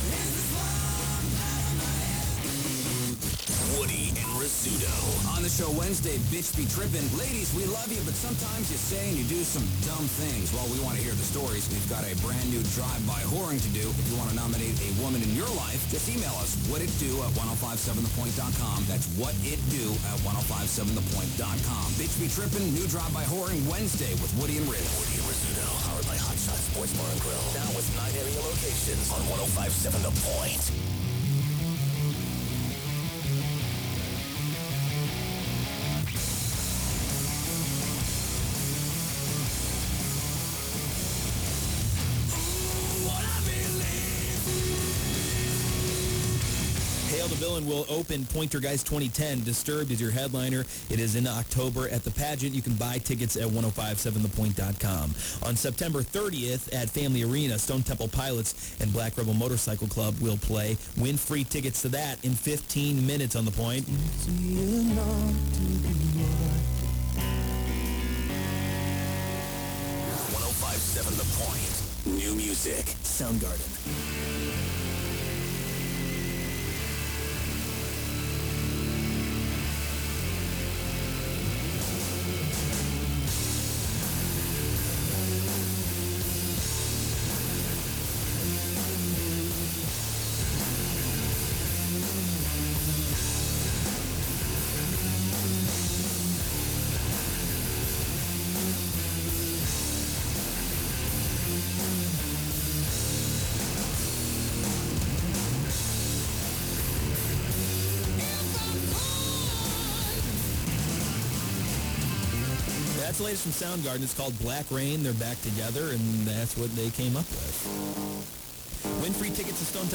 KPNT Unknown Aircheck · St. Louis Media History Archive